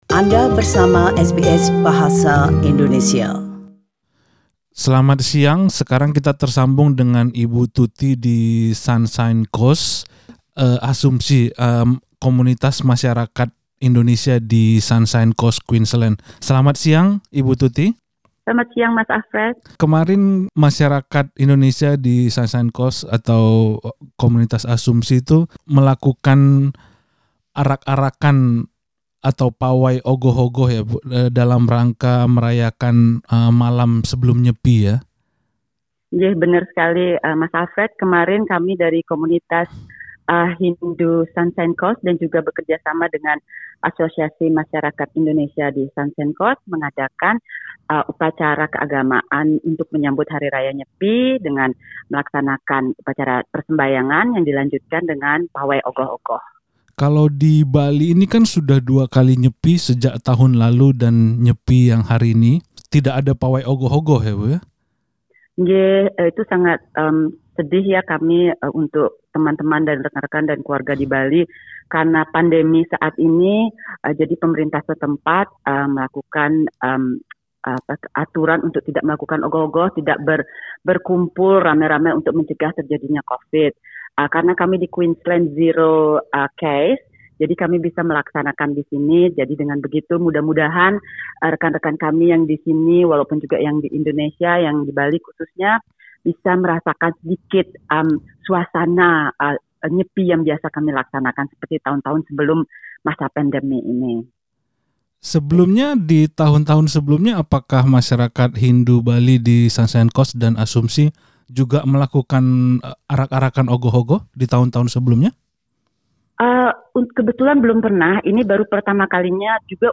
Berikut perbincangan dengan